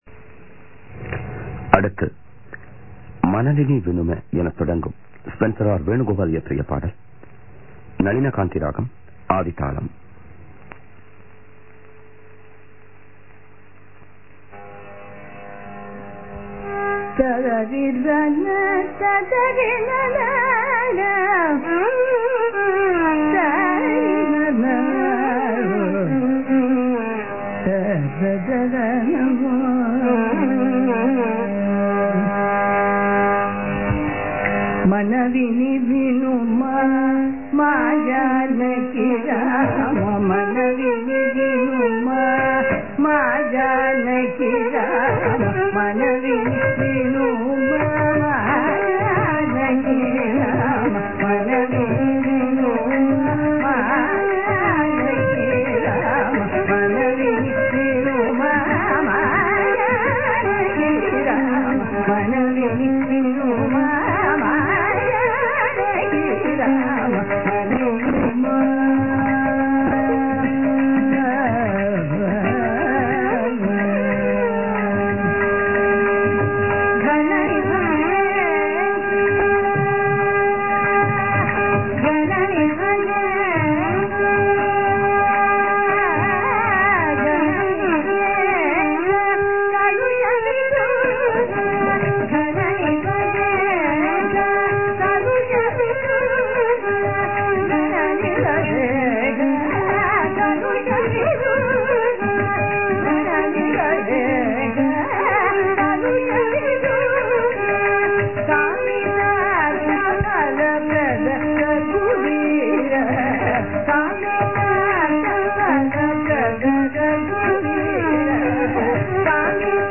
This broadcast recording
Violin
Mridangam
Ghatam